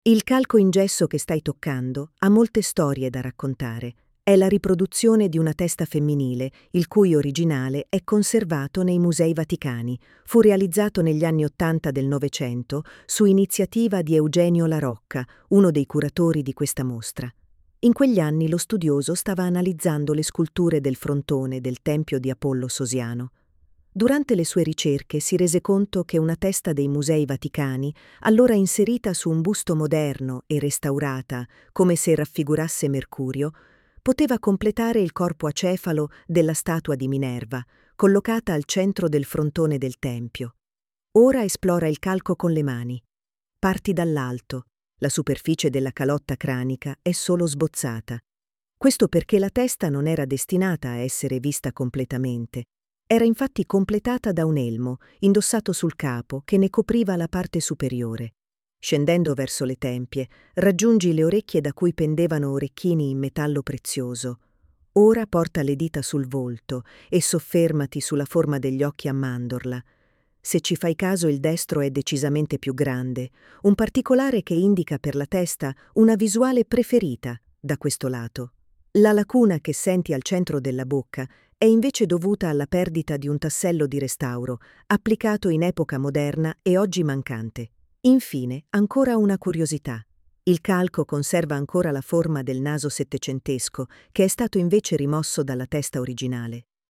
•    9 AUDIODESCRIZIONI che accompagnano il visitatore nell’esplorazione delle opere, ognuna indicata da didascalia in Braille e guida audio, con il relativo testo, fruibile tramite QR code: